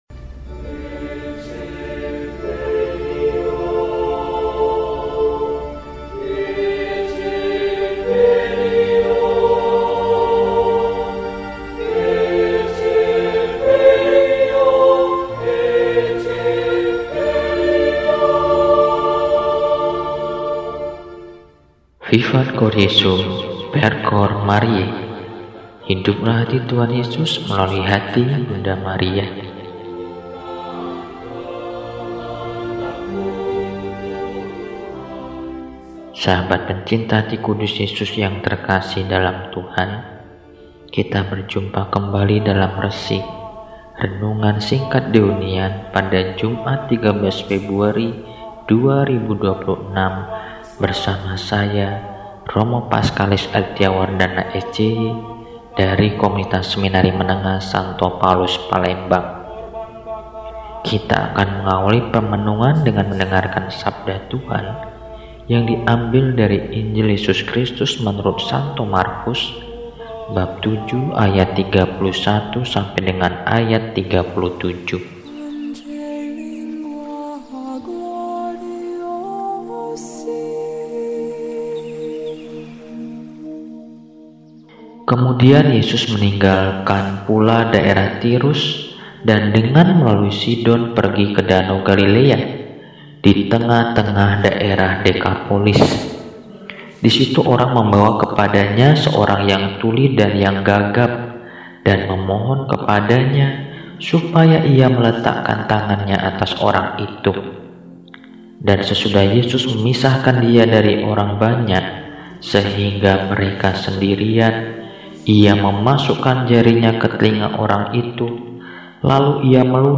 Jumat, 13 Februari 2026 – Hari Biasa Pekan V – RESI (Renungan Singkat) DEHONIAN